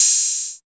99 OP HAT.wav